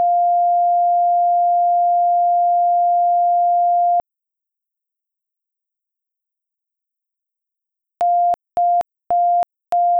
700hz.wav